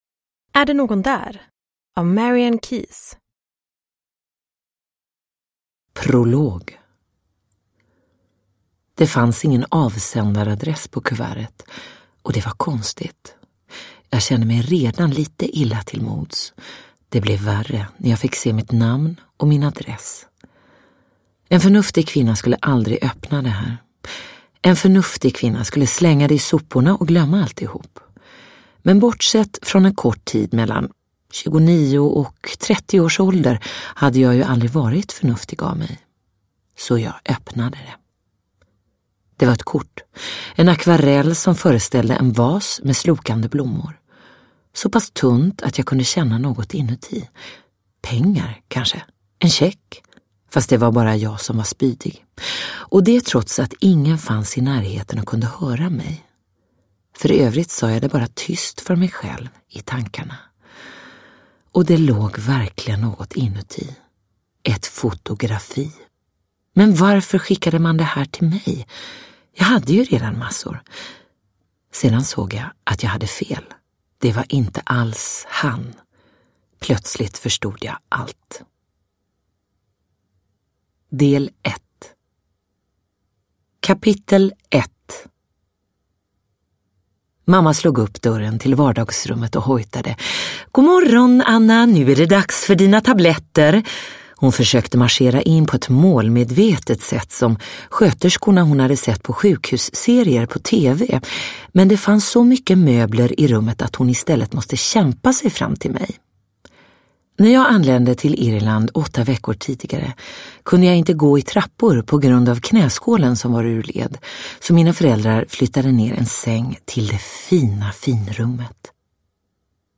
Är det någon där? – Ljudbok – Laddas ner